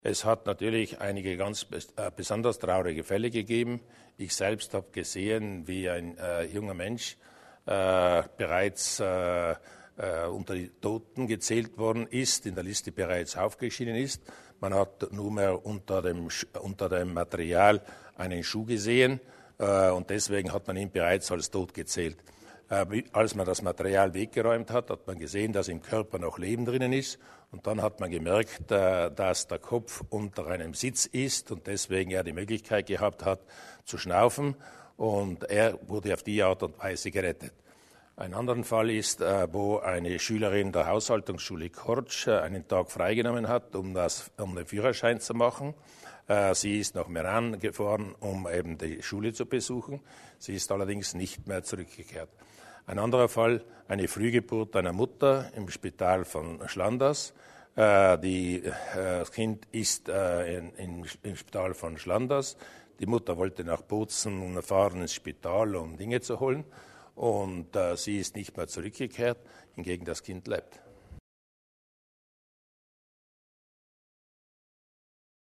Landeshauptmann Durnwalder über die Hilfe für die Familien